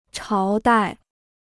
朝代 (cháo dài): dynasty; reign (of a king).